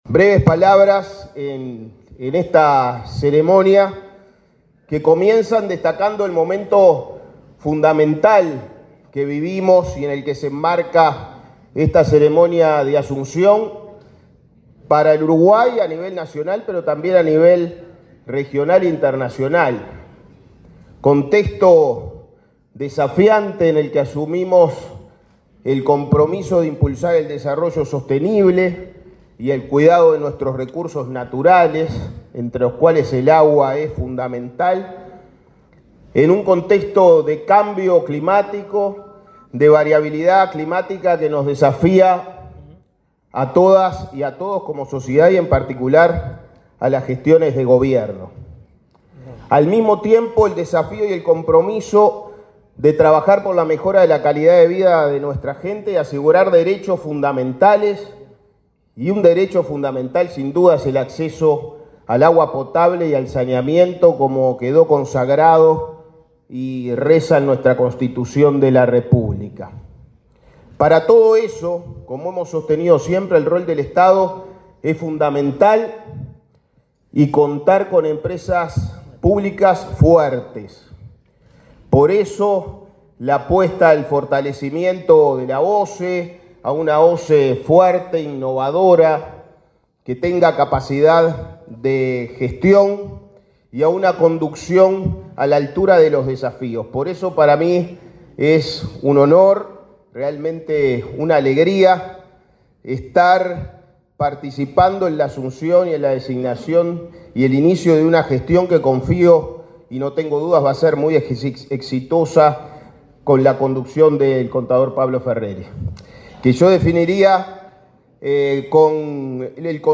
Palabras del ministro de Ambiente y del nuevo presidente de OSE
Palabras del ministro de Ambiente y del nuevo presidente de OSE 28/03/2025 Compartir Facebook X Copiar enlace WhatsApp LinkedIn El ministro de Ambiente, Edgardo Ortuño, y el nuevo presidente de la OSE, Pablo Ferreri, detallaron los lineamientos estratégicos de la gestión, durante el acto de asunción de las autoridades en el ente, realizado este viernes 28 en Montevideo.